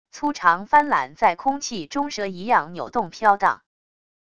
粗长帆缆在空气中蛇一样扭动飘荡wav音频